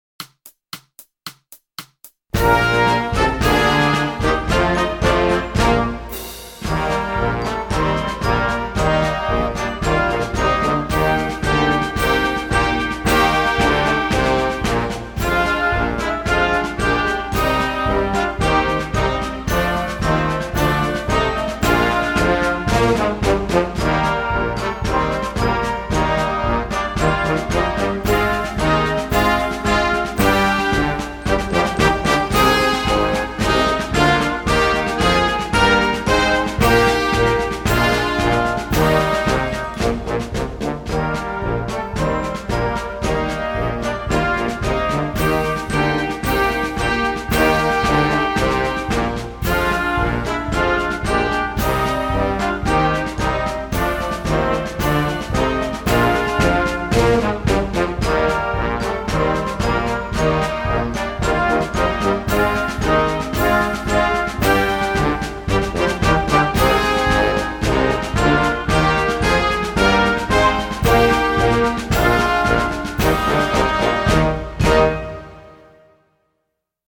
TROMBA SOLO • ACCOMPAGNAMENTO BASE MP3
Trombone